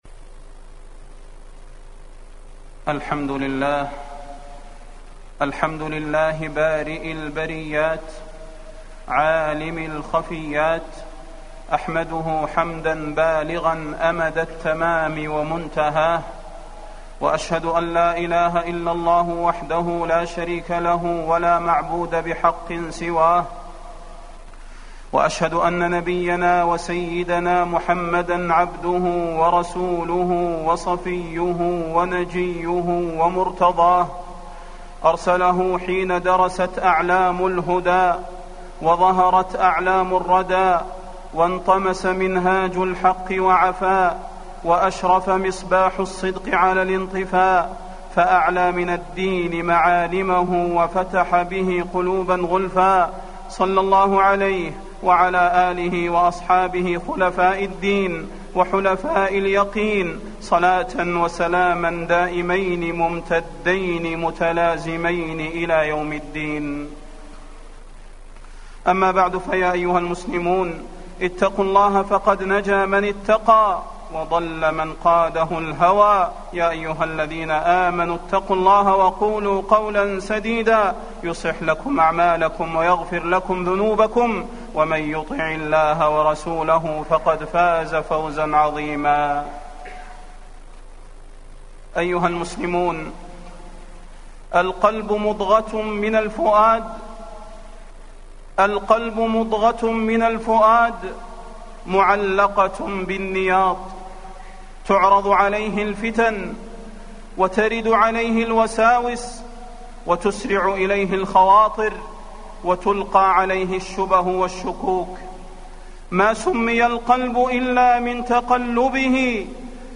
تاريخ النشر ١٣ ربيع الأول ١٤٢٩ هـ المكان: المسجد النبوي الشيخ: فضيلة الشيخ د. صلاح بن محمد البدير فضيلة الشيخ د. صلاح بن محمد البدير تقلب القلوب عند الفتن